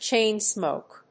アクセントcháin‐smòke